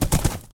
should be correct audio levels.
gallop4.ogg